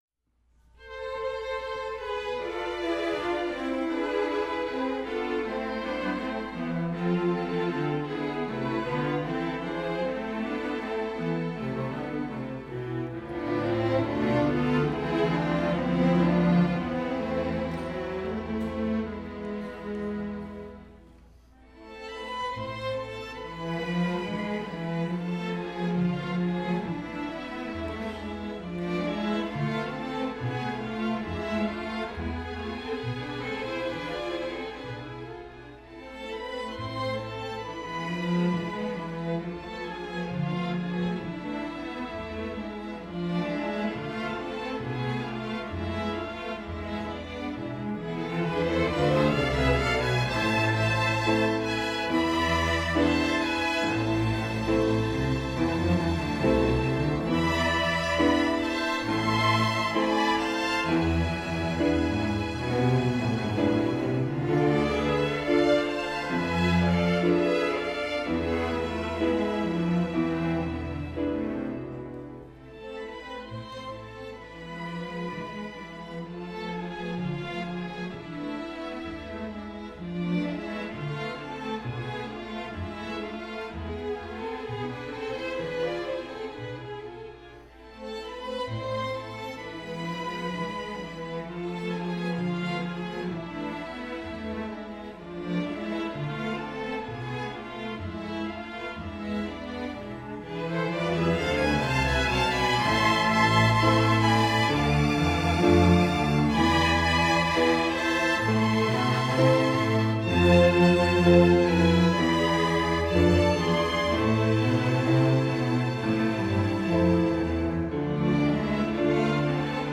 Instrumentation: Piano and string orchestra
Ensemble: String Orchestra